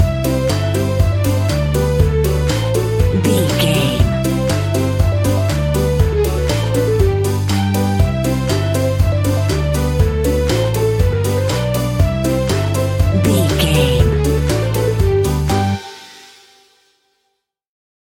Uplifting
Ionian/Major
Fast
childlike
happy
kids piano